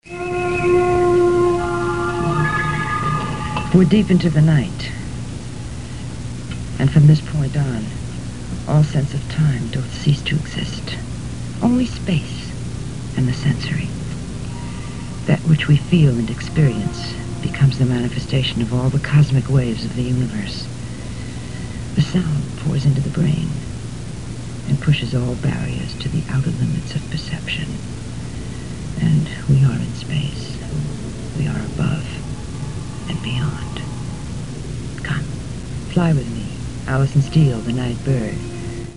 POST IS UPDATED WITH HER VOICE – very mind-blowing !
She would start her show reciting poetry over Andean flute music, then introduce her show in her well-known sultry, smoky voice: